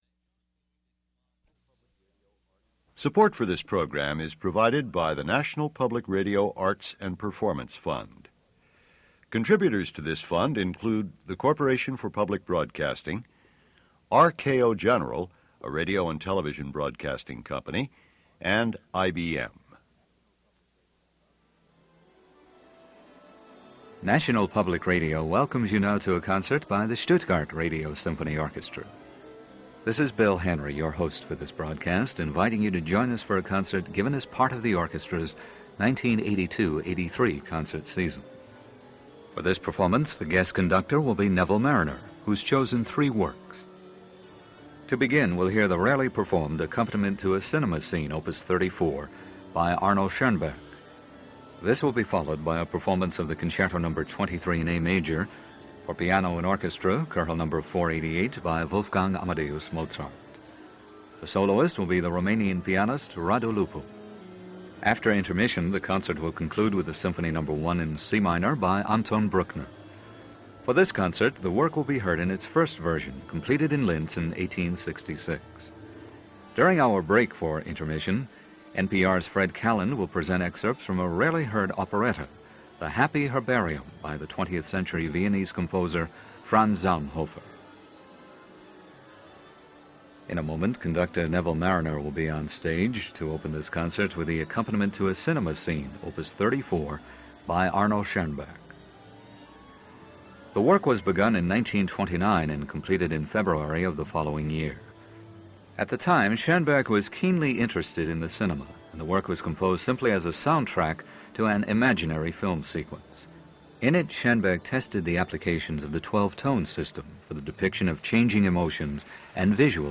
Radu Lupu With Sir Neville Marriner And The Stuttgart Radio Symphony In Concert - 1983 - Past Daily Mid-Week Concert: Historic Performances
Sir Neville Marriner, Cond. – Radu Lupu, Piano
Starting the concert with the seldom performed Accompaniment to a Cinematographic Scene, composed between 1929 and 1930 by Arnold Schoenberg. Followed by Mozart’s Piano Concerto Number 23, with Rumanian Pianist Radu Lupu. The concert concludes with the Symphony Number 1 by Anton Bruckner.